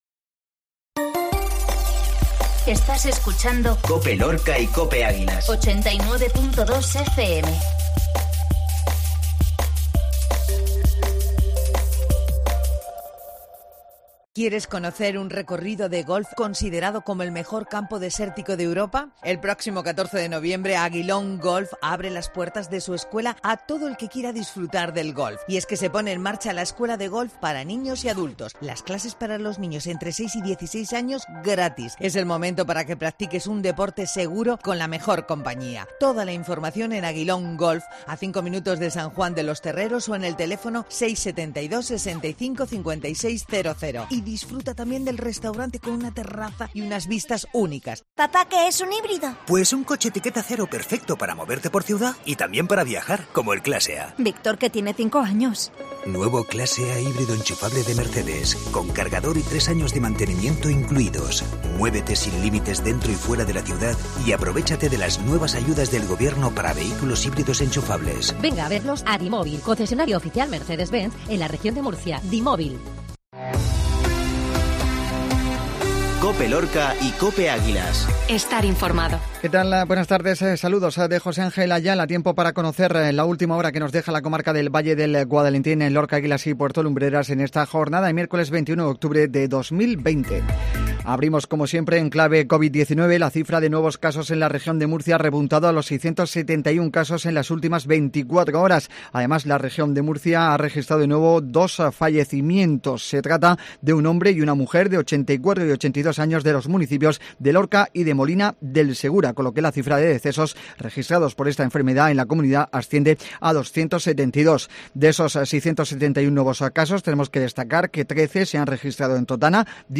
INFORMATIVO MEDIODIA COPE LORCA